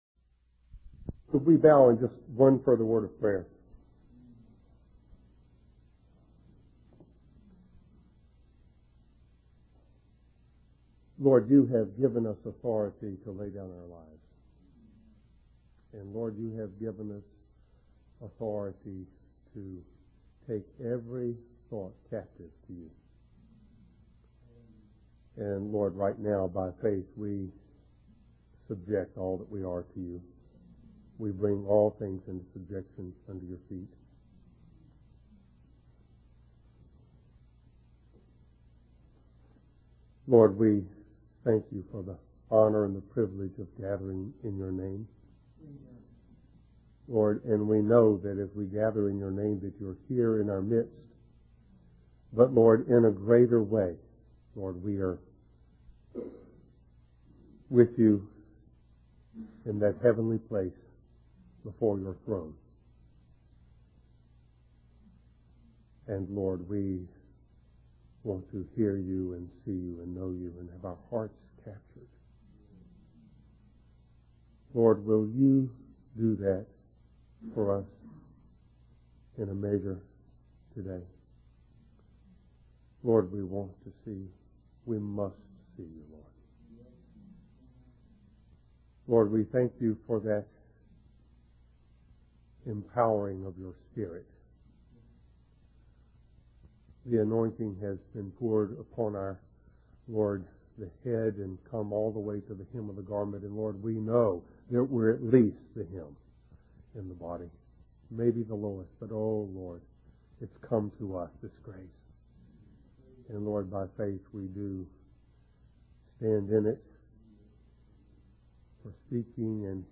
Western Christian Conference, Santa Barbara 2001